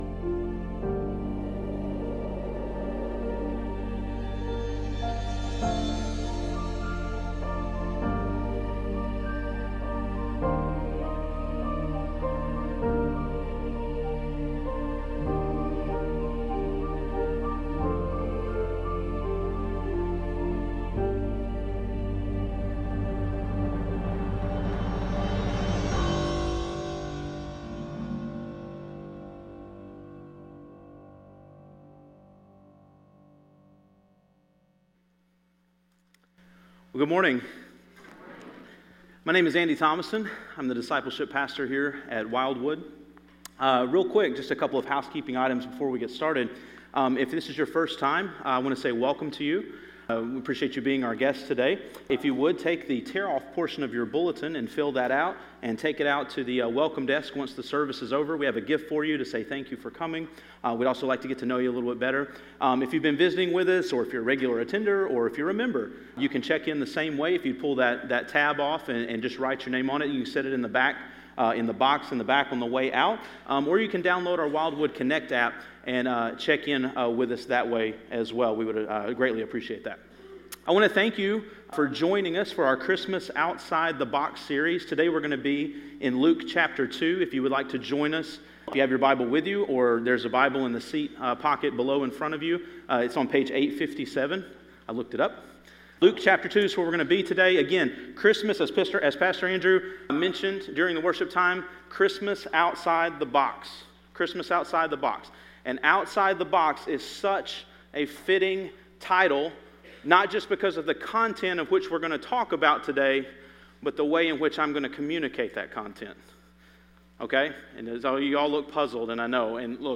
A message from the series "To Seek and To Save."